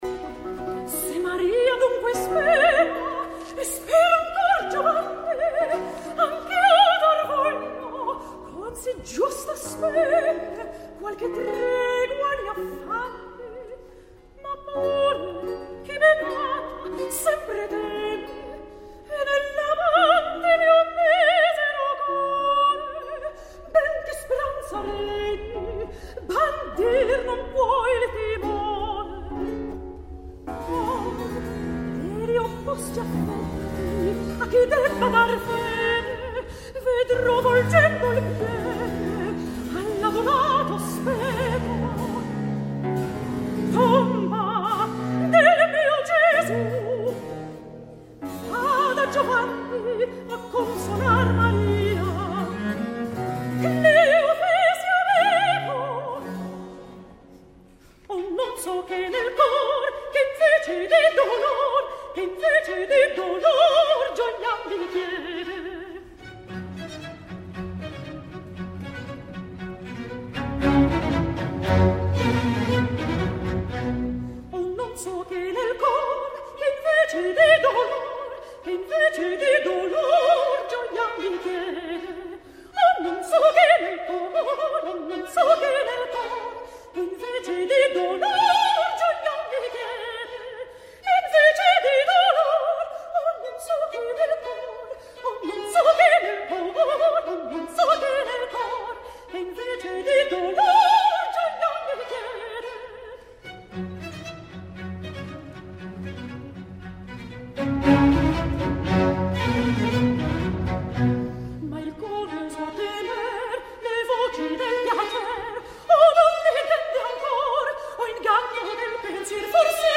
La seva veu de soprano és acompanyada per violins a l’uníson amb la seva melodia; amb ritornellos instrumentals intervenint  entre les seccions, amb una lleugeresa ingràvida de quasi santedat.
Escoltem a Sophie Karthäuser (Maria Magdalena) cantant “Ho un non so che nel cor”
Sophie Karthäuser – Maria Magdalena (soprano)
Le Cercle de l’Harmonie
Director: René Jacobs
Sala Karol Szymanowski, Cracòvia 21 d’abril de 2014.
Ària: “Ho un non so che nel cor” (Maria Magdalena)